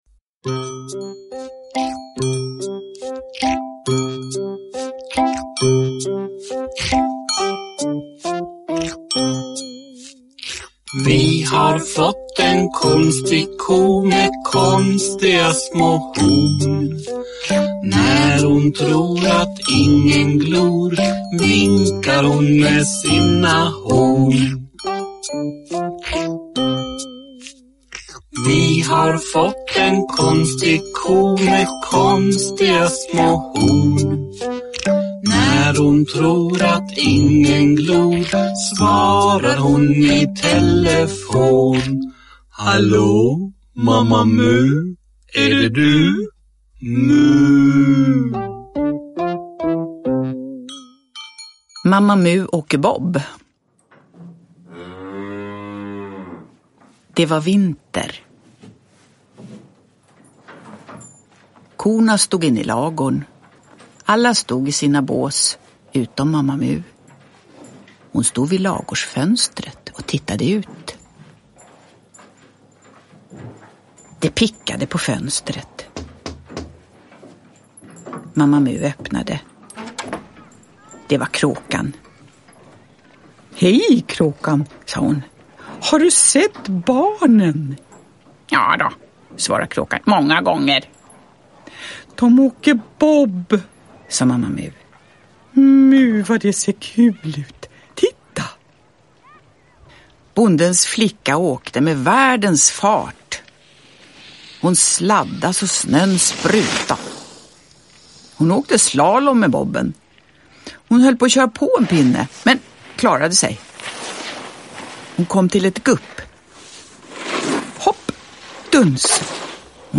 Uppläsning med musik.
Uppläsare: Jujja Wieslander